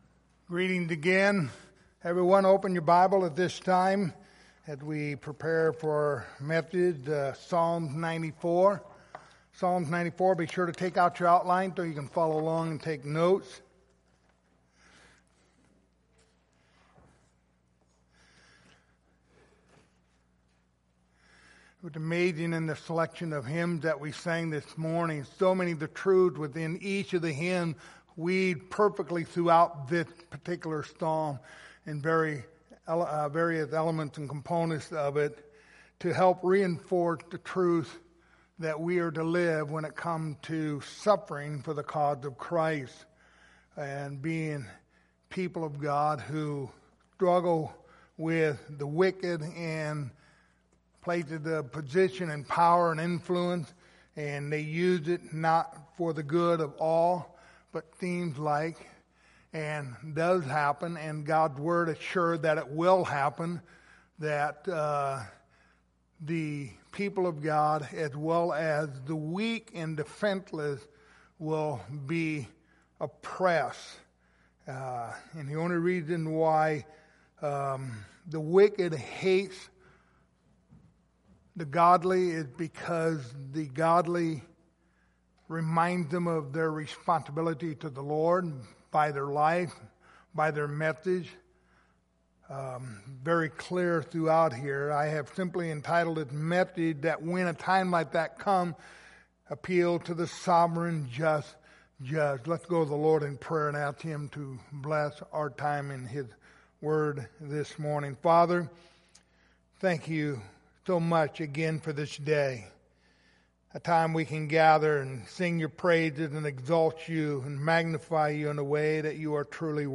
The book of Psalms Passage: Psalms 94:1-23 Service Type: Sunday Morning Topics